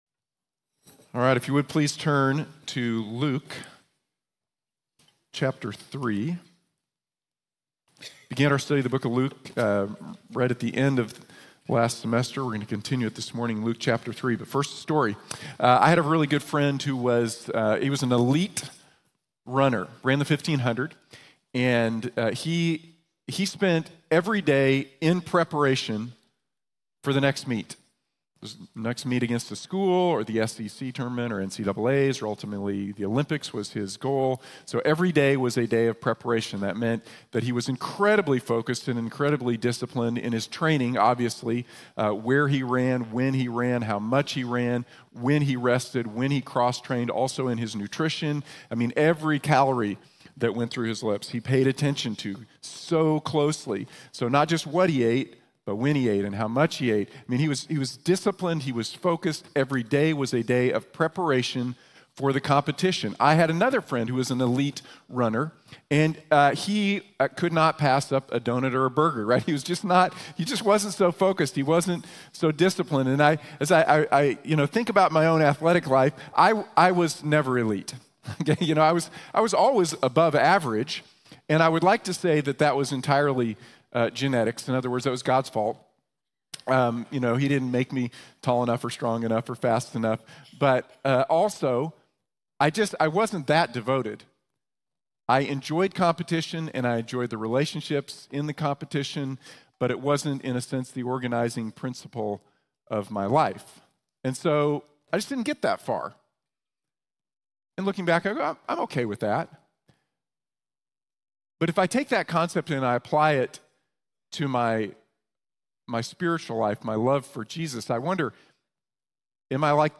Prepárense para el Rey Jesús | Sermón | Iglesia Bíblica de la Gracia